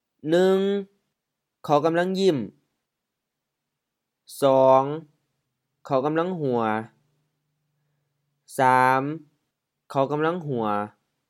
khao M
gam-laŋ M-HR